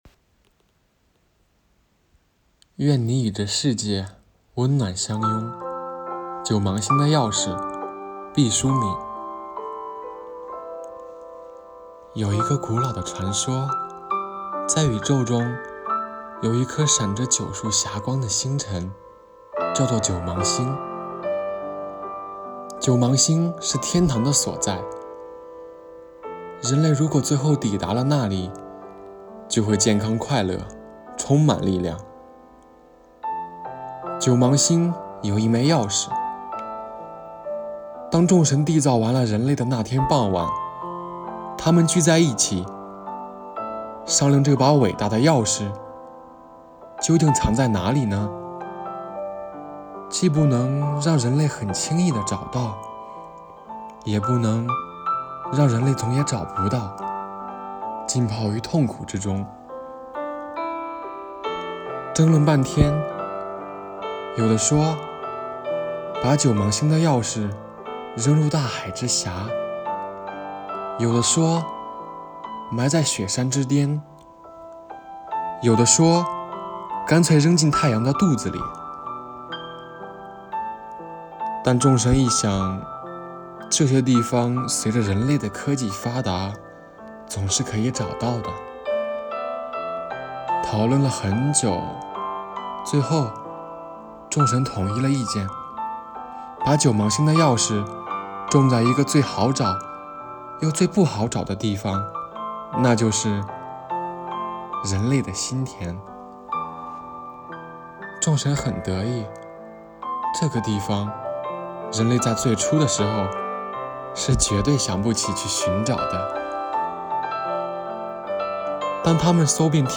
“阅读的力量 -- 读给你听”主题朗诵